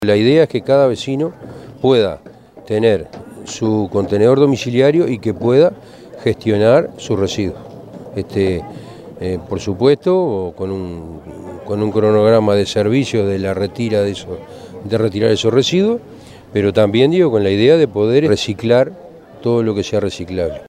alcides_perez_-_alcalde_municipio_de_pando.mp3